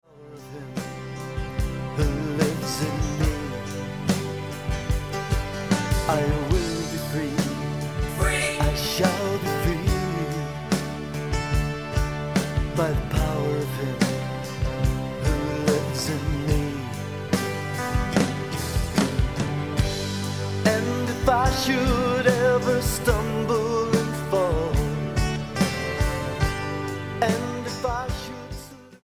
Celtic rock